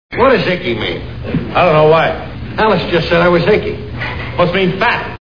The Honeymooners TV Show Sound Bites